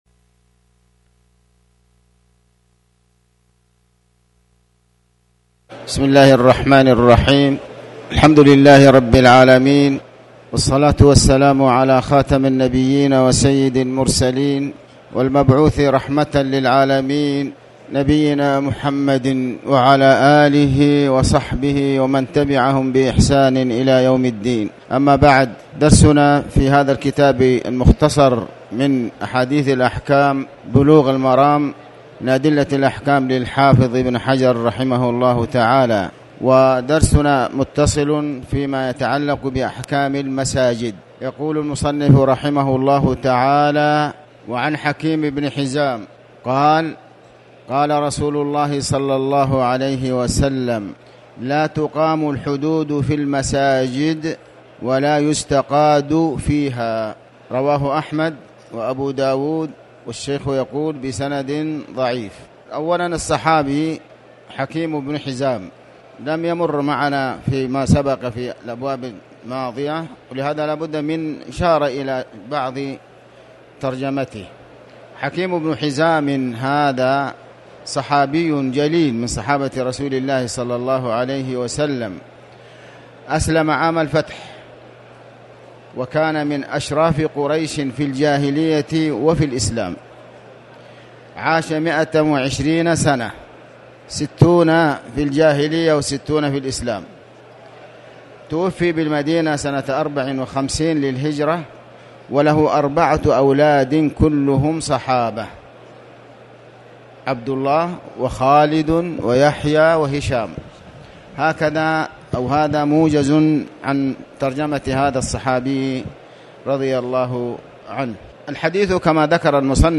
تاريخ النشر ١٧ شعبان ١٤٣٩ هـ المكان: المسجد الحرام الشيخ